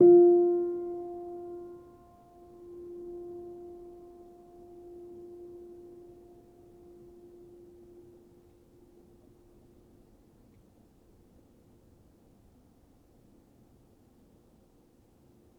healing-soundscapes/Sound Banks/HSS_OP_Pack/Upright Piano/Player_dyn1_rr1_022.wav at main